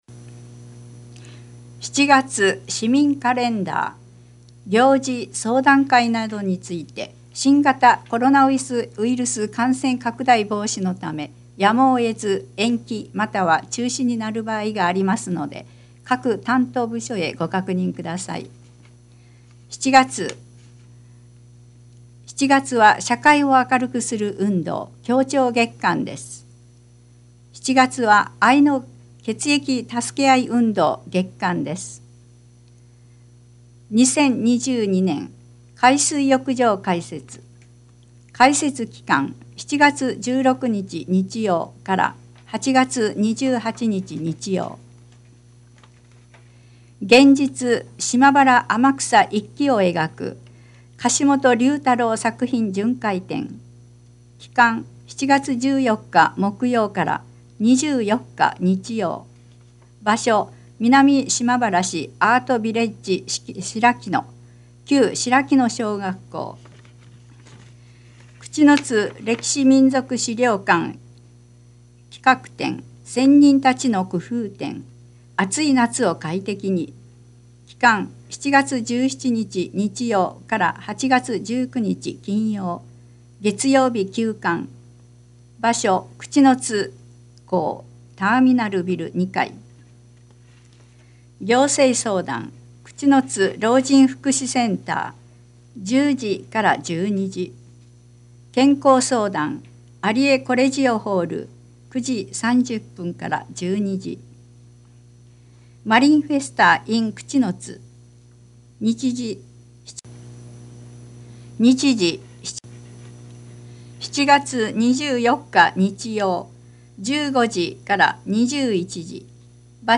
音訳（※声の広報紙）